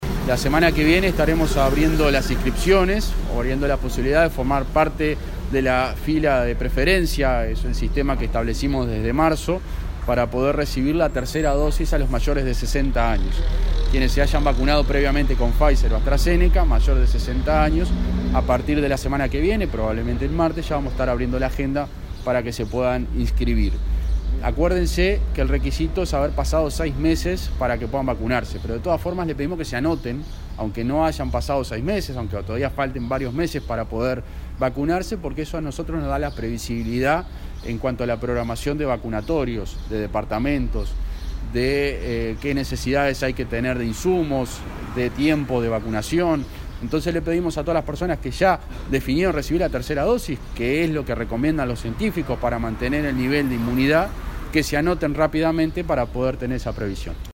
Lo anunció el subsecretario de Salud, José Luis Satdjian, quien en rueda de prensa dijo este martes que «la semana que viene estaremos abriendo las inscripciones, o viendo la posibilidad de formar parte de la fila de preferencia. Es un sistema que establecimos desde marzo para poder recibir la tercera dosis a los mayores de 60 años».